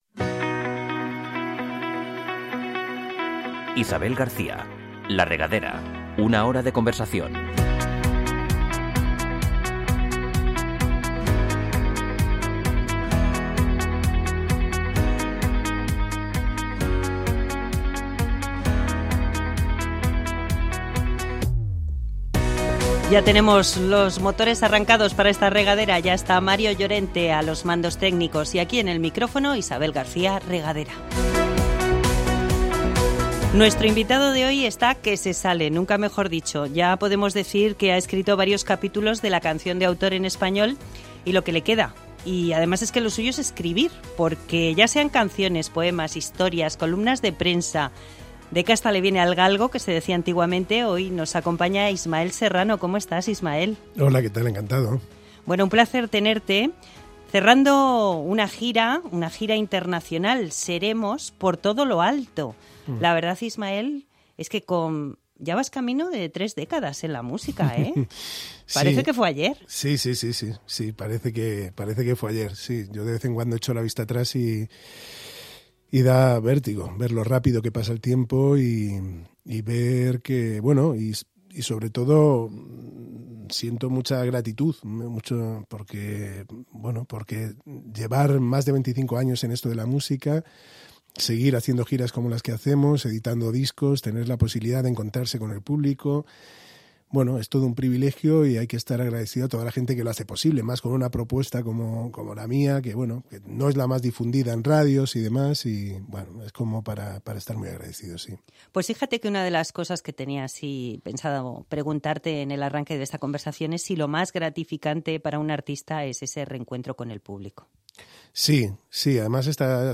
Un espacio para conversar, con buena música de fondo y conocer en profundidad a todo tipo de personajes interesantes y populares.